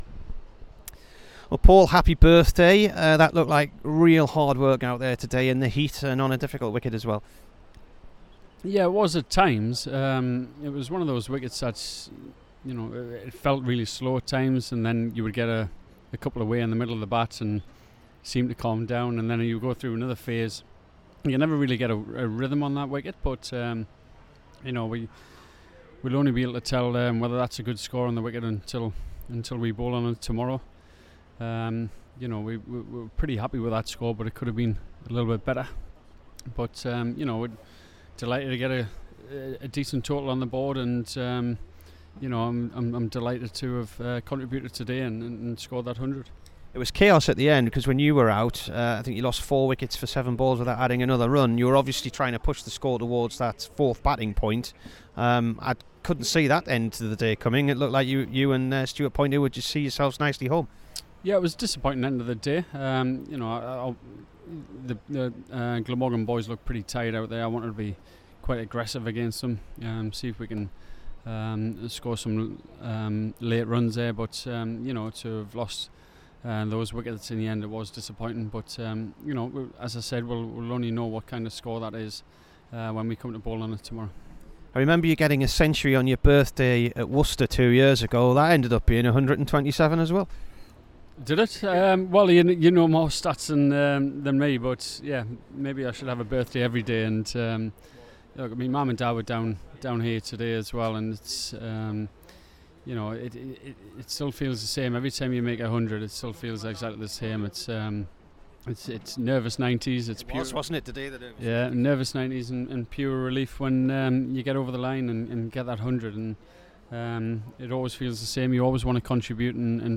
PAUL COLLINGWOOD INT
HERE IS THE DURHAM SKIPPER AFTER HIS 127 ON HIS 41ST BIRTHDAY V GLAMORGAN